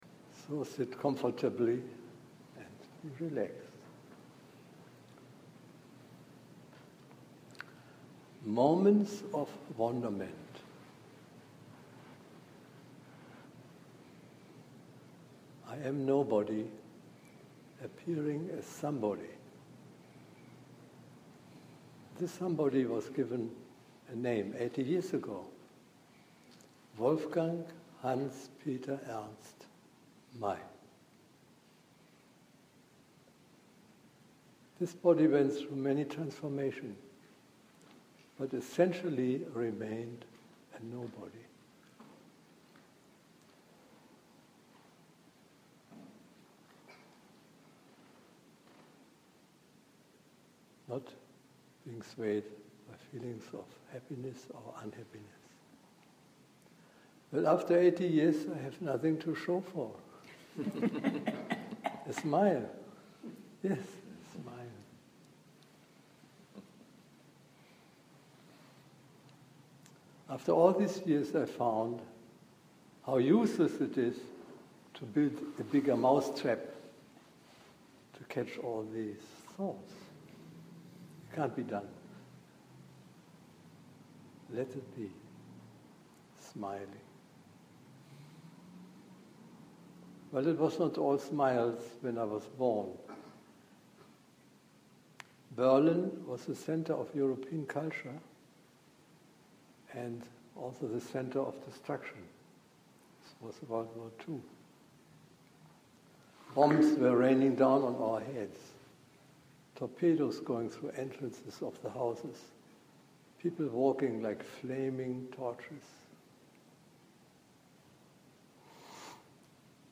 Moments of Wonderment: Dharma Talk